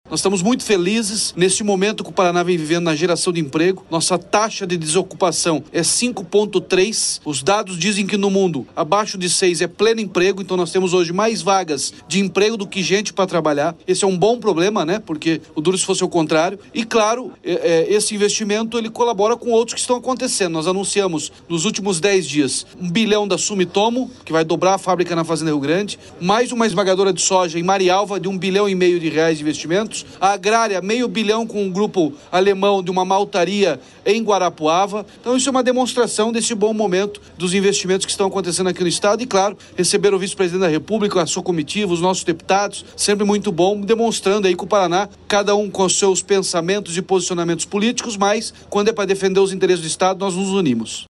Sonora do governador Ratinho Junior sobre o investimento de R$ 1,7 bilhão do Grupo Potencial para ampliar a produção de biodiesel no Paraná